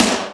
Snaredrum-08.wav